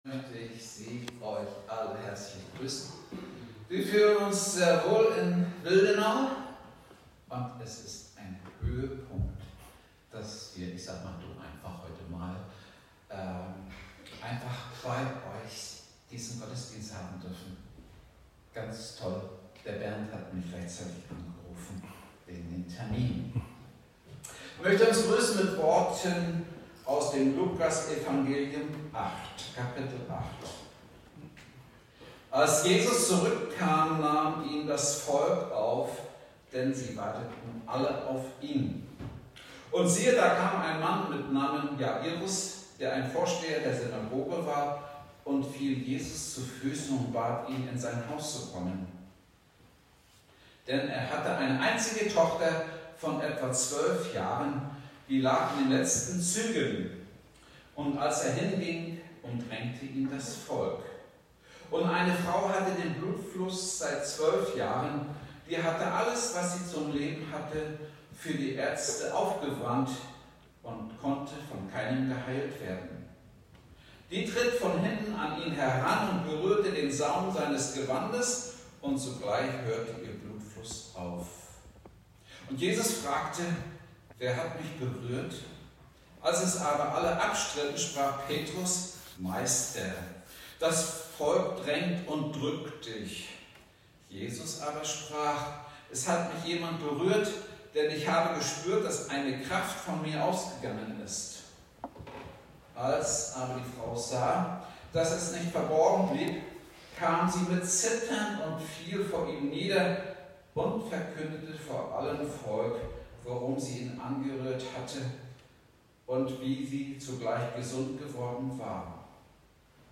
Gottesdienstart: Predigtgottesdienst